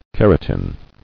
[ker·a·tin]